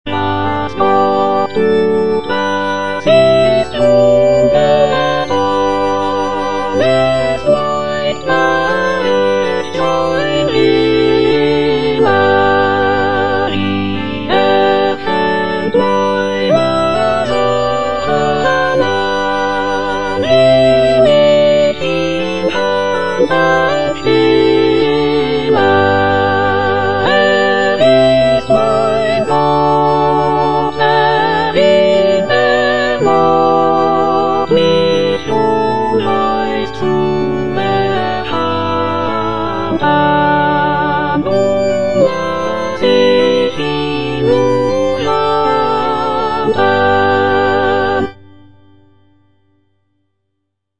Cantata
Soprano (Emphasised voice and other voices) Ads stop